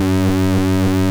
BUCHLA F#3#.wav